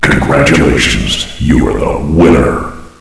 flak_m/sounds/announcer/int/winner.ogg at 9e43bf8b8b72e4d1bdb10b178f911b1f5fce2398